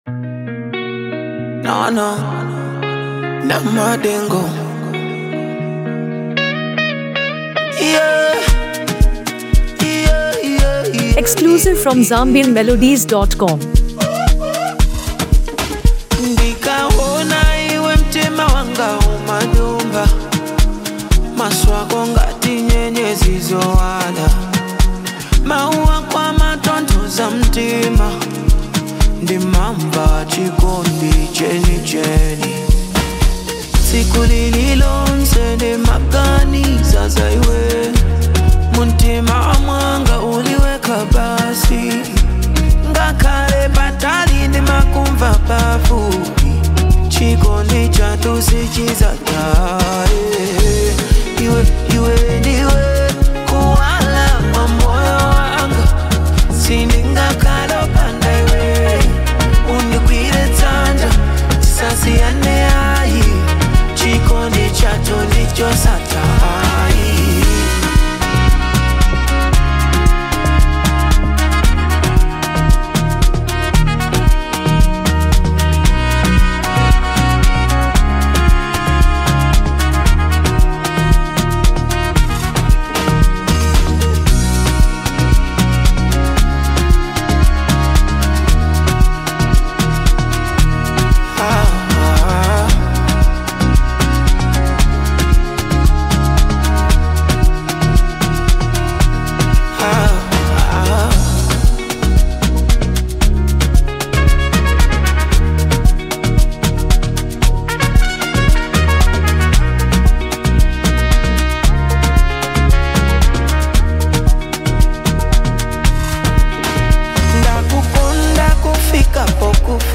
Inspirational Afro-Pop
Genre: Afro-Fusion